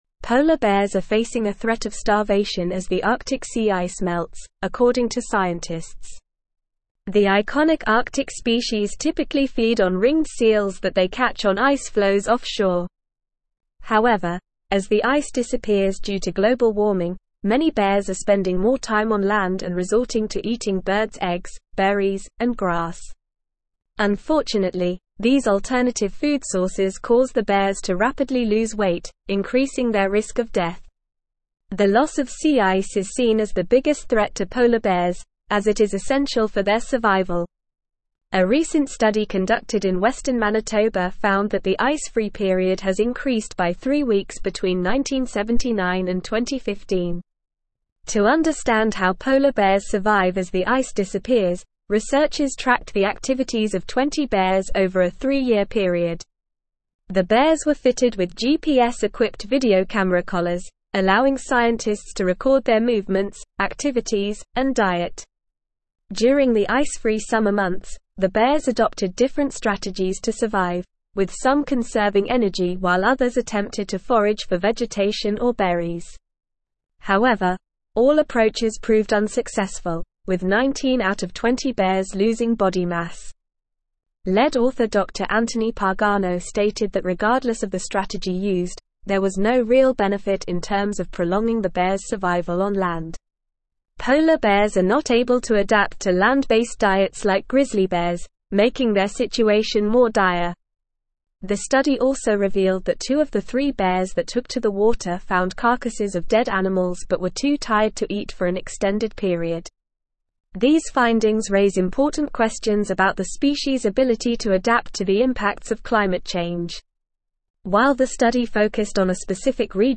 Normal
English-Newsroom-Advanced-NORMAL-Reading-Polar-bears-face-starvation-as-Arctic-sea-ice-melts.mp3